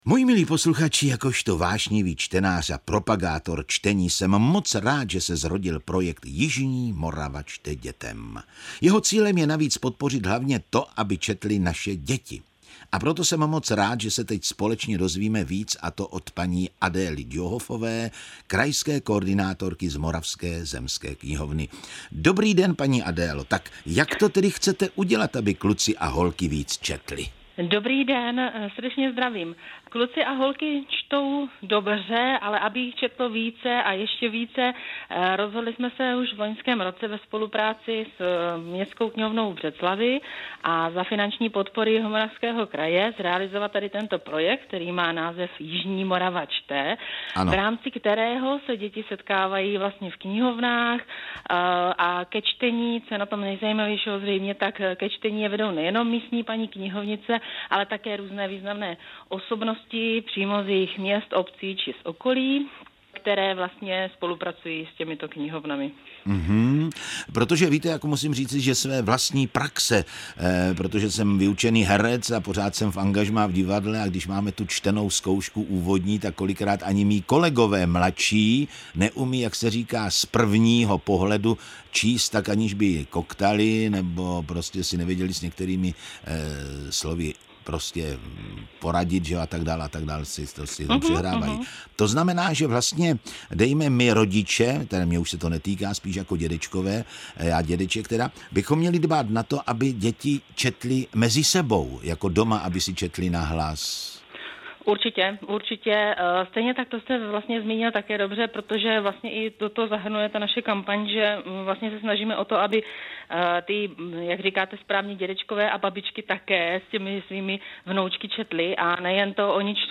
[Interview o projektu Jižní Morava čte].